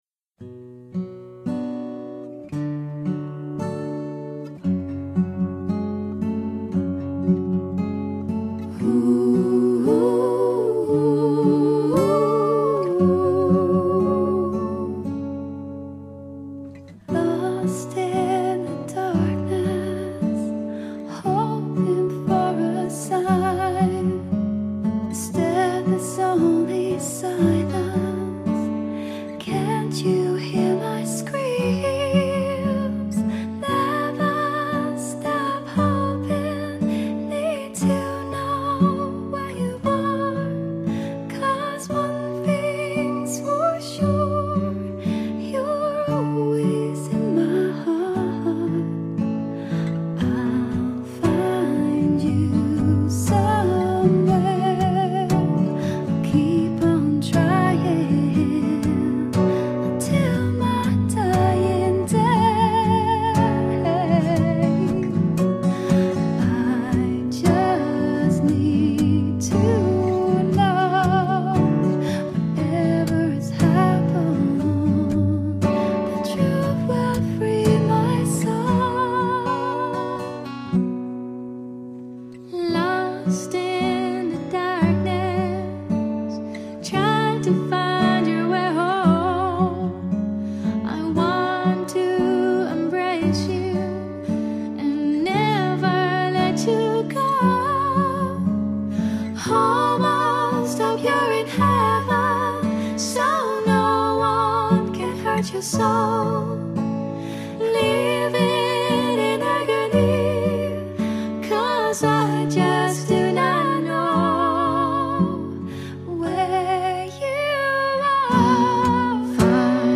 медлячок для спокойствия